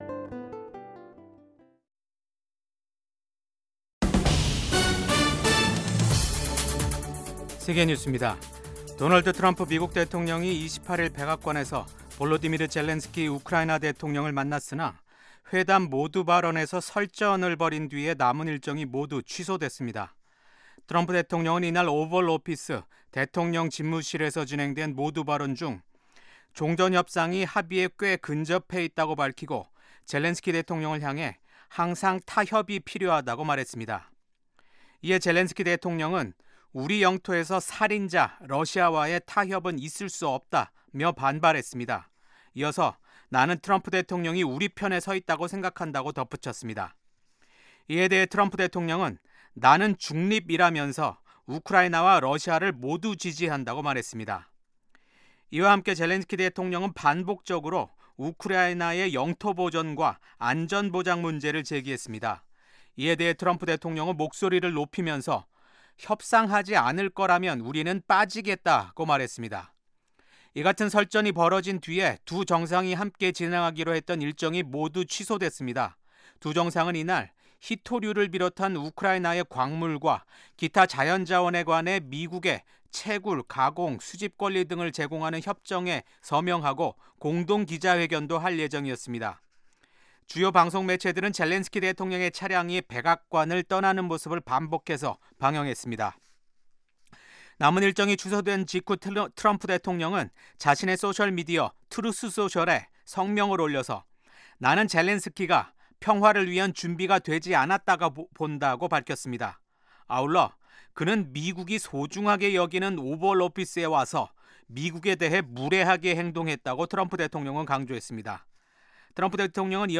VOA 한국어 아침 뉴스 프로그램 '워싱턴 뉴스 광장'입니다. 북한이 김정은 국무위원장 참관 아래 서해상으로 전략순항미사일 발사 훈련을 한 가운데, 미한 연합훈련을 앞둔 압박 메시지를 담고 있다는 분석이 나옵니다. 미국 정부가 사상 최대 규모인 15억 달러 상당의 암호화폐를 탈취한 ‘바이비트’ 해킹 사건이 북한 해킹 조직의 소행임을 공식적으로 확인했습니다.